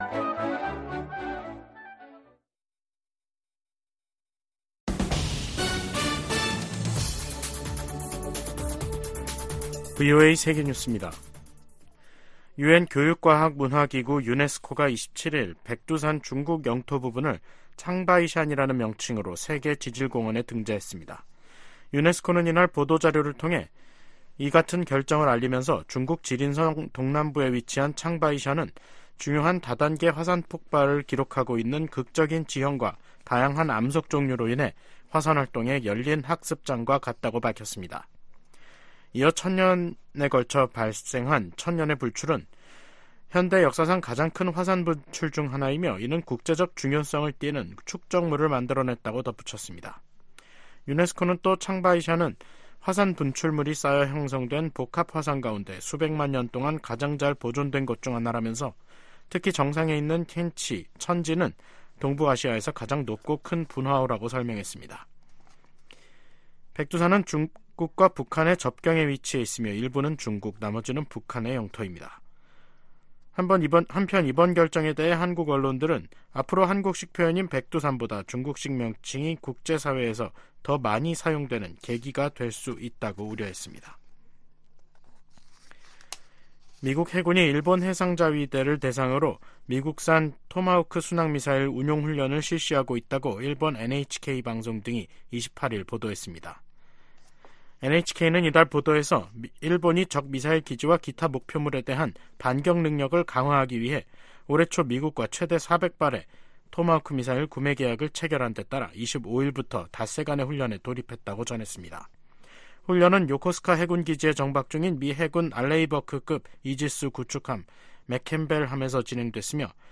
VOA 한국어 간판 뉴스 프로그램 '뉴스 투데이', 2024년 3월 28일 3부 방송입니다. 미국과 한국이 공동으로 북한 국적자 6명과 외국업체 2곳에 대한 제재를 단행했습니다. 북한이 최근 원심분리기 시설을 확장하고 있는 것으로 보인다는 보도가 나온 가운데 미국 정부는 위험 감소 등 북한과 논의할 것이 많다는 입장을 밝혔습니다. 중국이 미일 동맹 격상 움직임에 관해, 국가 간 군사협력이 제3자를 표적으로 삼아선 안 된다고 밝혔습니다.